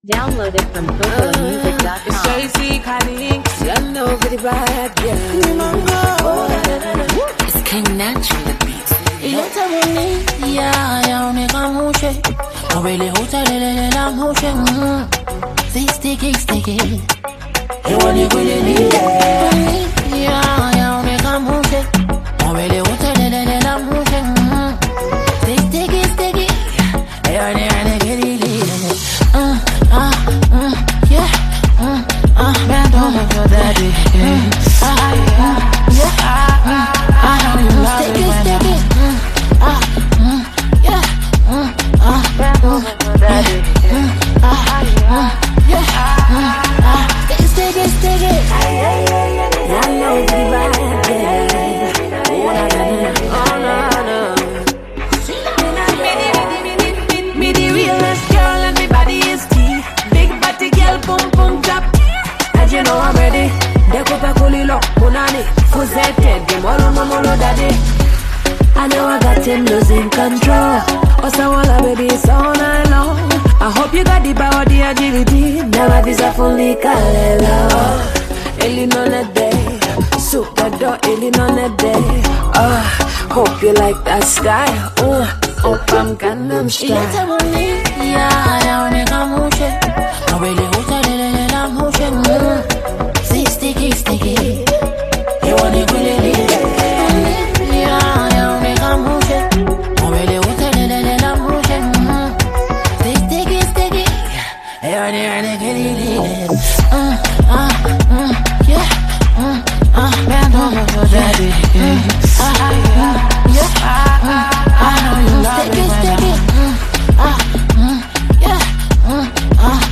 a sweet and romantic song